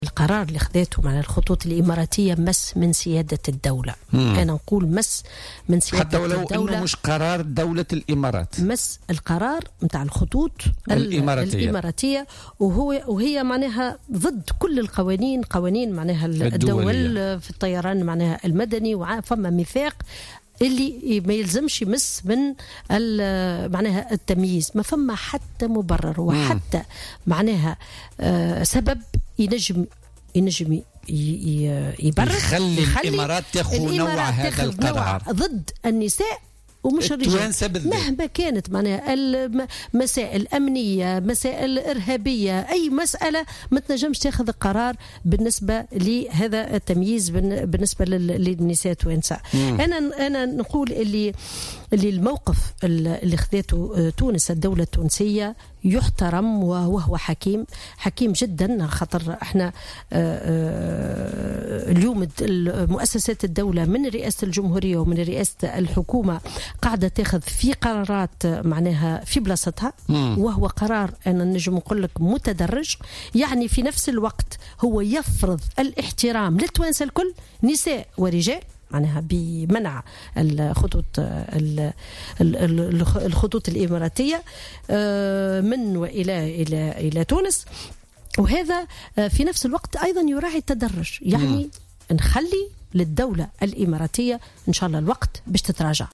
وأضافت ضيفة برنامج 'بوليتيكا' اليوم الاثنين 25 ديسمبر 2017، أن قرار الإماراتية منع تونسيات من السفر إليها وعبرها، غير مبرر مهما كانت الأسباب التي تقف خلفه، كما اعتبرته يمسّ من سيادة الدولة التونسية.